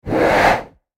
CloseHandheldTorch PE361701
Close Handheld Torch Whoosh, X6